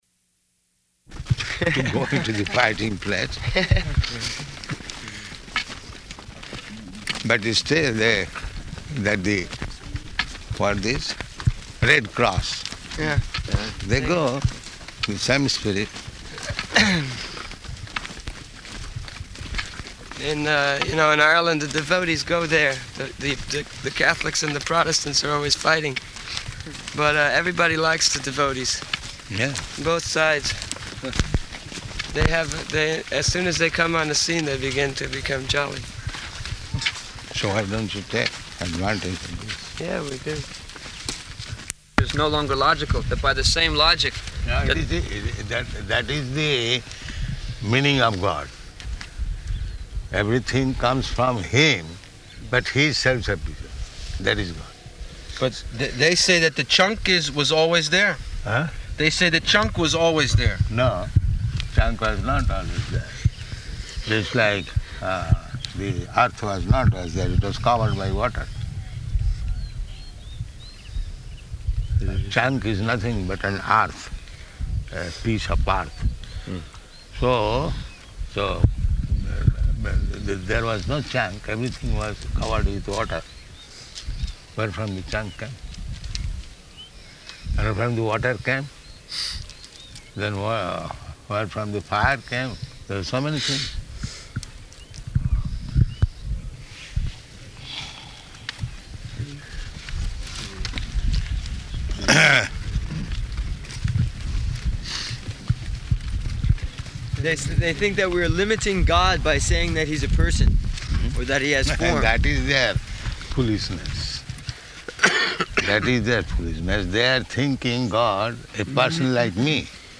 Morning Walk --:-- --:-- Type: Walk Dated: April 3rd 1975 Location: Māyāpur Audio file: 750403MW.MAY.mp3 Prabhupāda: ... go into the fighting place.